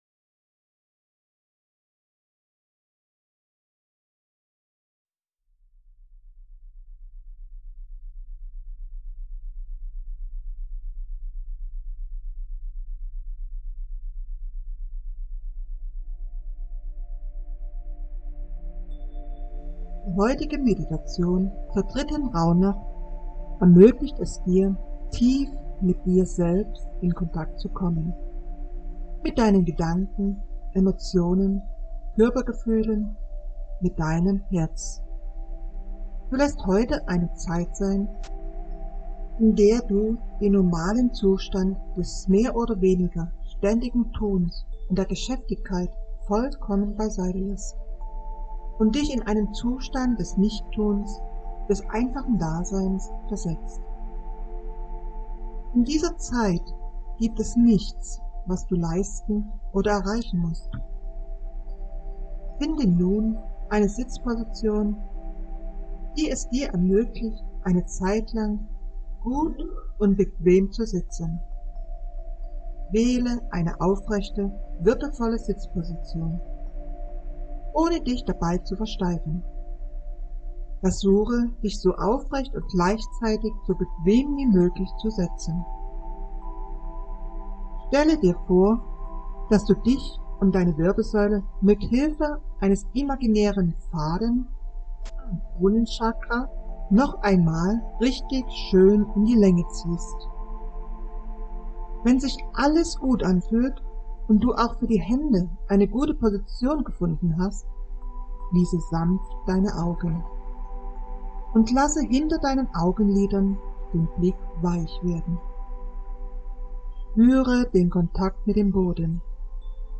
Das Clearing für die heutige Meditation ist: